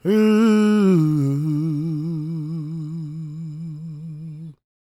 GOSPMALE037.wav